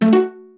jingles-pizzicato_02.ogg